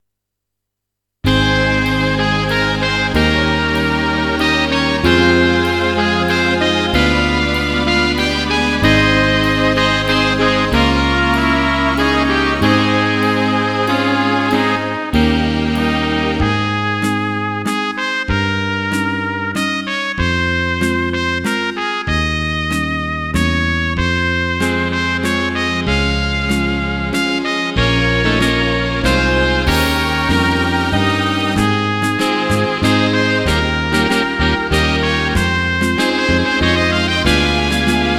Rubrika: Národní, lidové, dechovka
- waltz